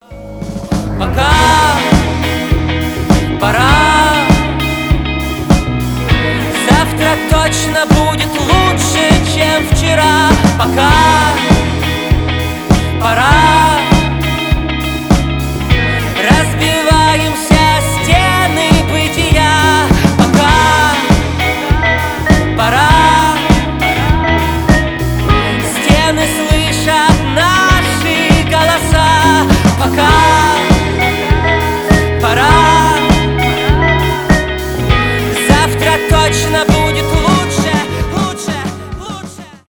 альтернатива , рок , indie rock